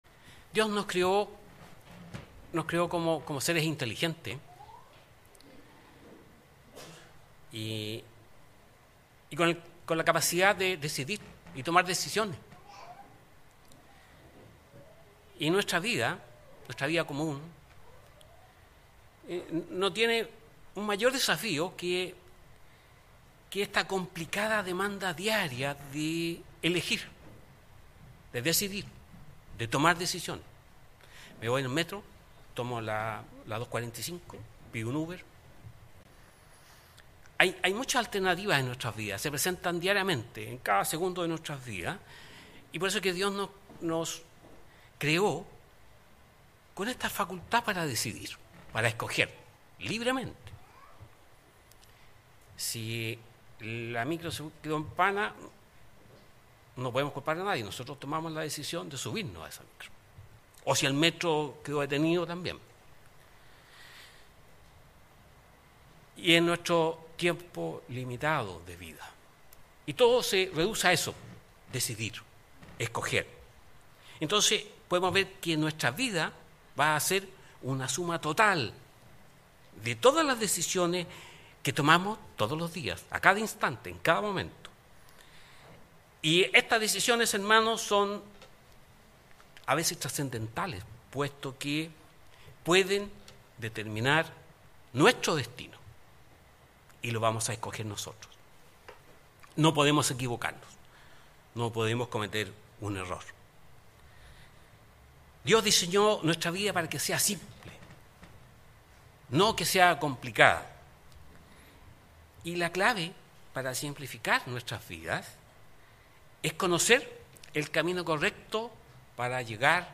Mensaje entregado el 1 de julio de 2023.